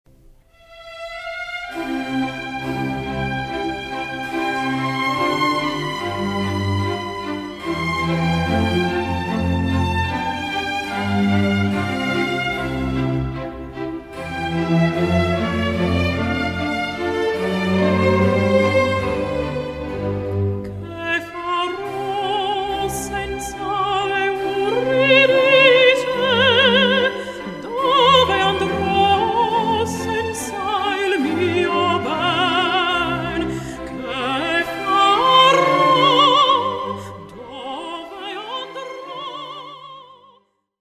Here is a beautiful aria from this opera:
Listen again to the aria, and never mind that it is in major, never mind that the singing is quite simple and not boiling over with emotions.